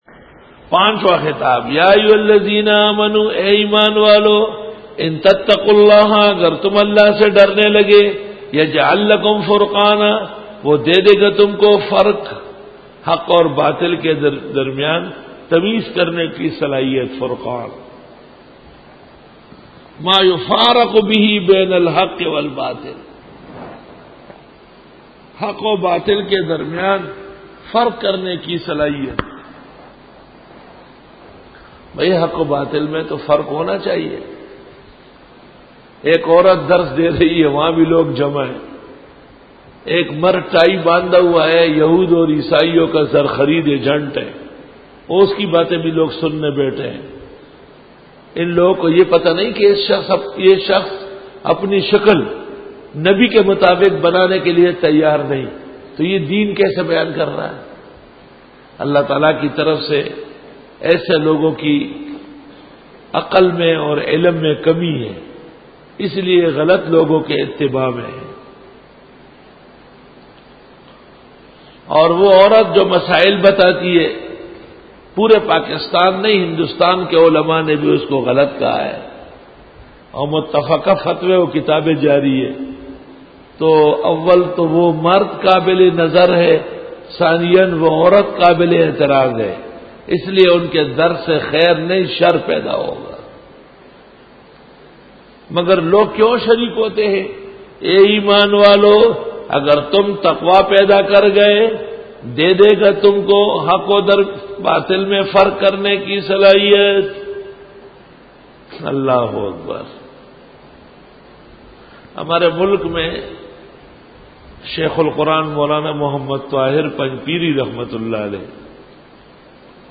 سورۃ الانفال رکوع-04 Bayan